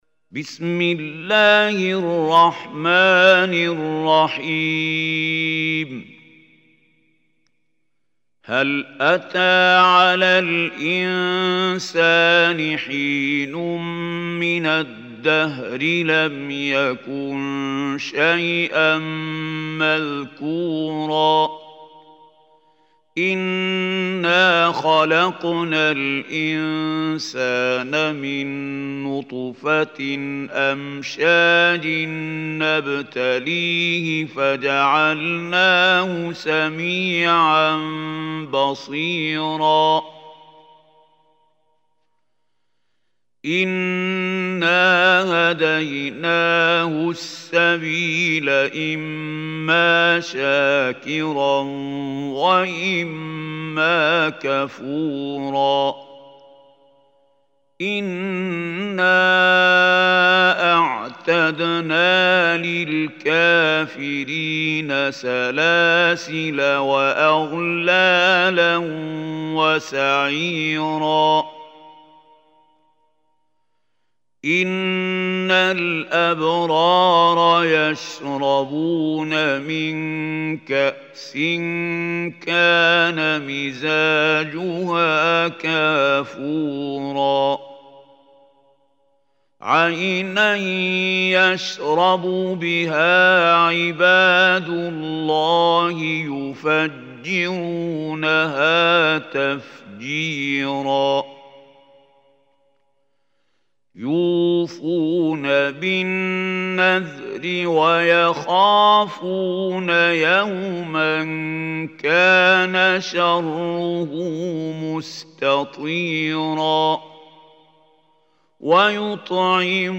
Surah Insan Recitation by Mahmoud Khalil Hussary
Surah Insan is 76 surah of Holy Quran. Listen or play online mp3 tilawat / recitation in Arabic in the beautiful voice of Sheikh Mahmoud Khalil Al Hussary.
076-surah-insan.mp3